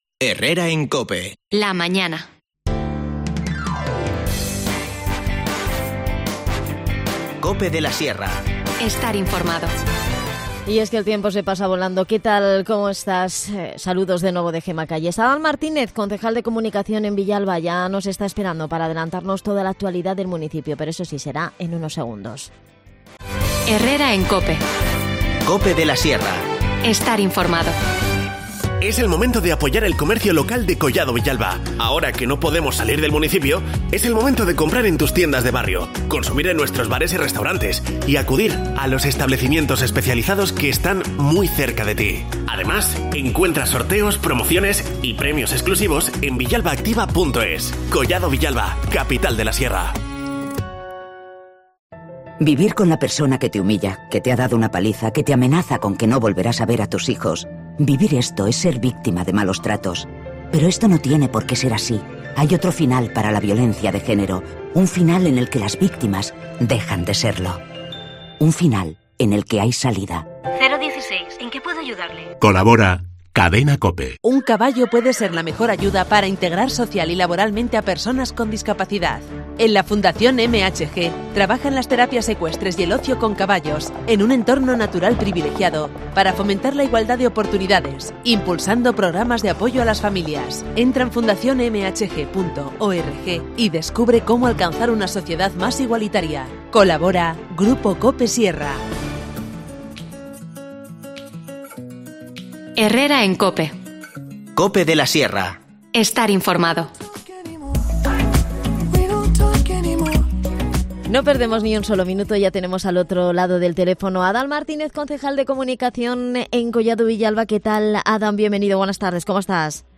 AUDIO: Adan Martínez, concejal de Comunicación en Collado Villalba, repasa toda la actualidad del municipio que pasa por las acciones que han puesto...